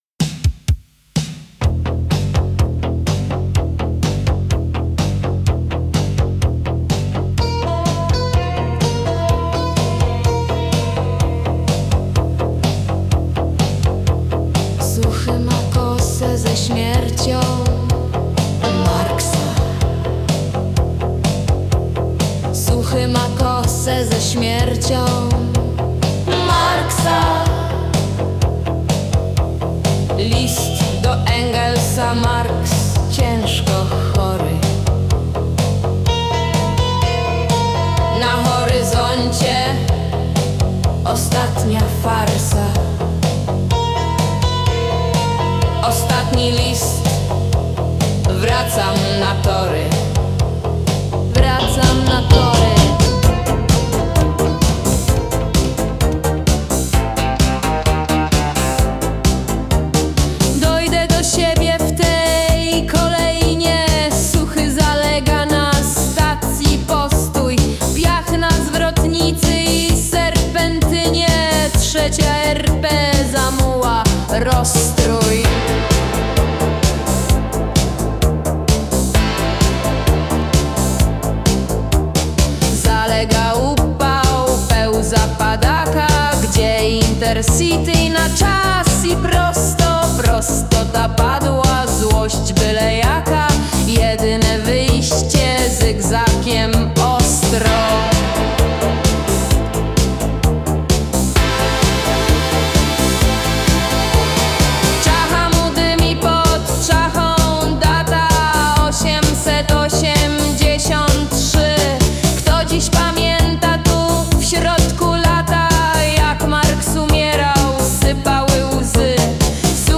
„Marksizm” jest słowem straszakiem w debacie publicznej, dlatego bohater piosenki to marksistowski macho – ale o jego rozbojach opowiada głos żeński, sojuszniczo ironizujący.
Dlatego pilnowałem, by AI wygenerowała relatywnie popową melodię. Podobno pop musi być „urozmaicony” – mój będzie „monotonny”.
Wokół rośnie nacisk, by śpiewające kobiety wyrażały głównie emocje – dlatego w moim kawałku wokalistka formułuje wyłącznie obserwacje i przemyślenia. Śpiewa beznamiętnie, a tekst artykułuje sarkastycznie i „płasko”.
Raczej „punkowo” agresywny.